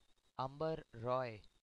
pronunciation (5 June 1945 – 19 September 1997) was an Indian cricketer who played in four Test matches in 1969.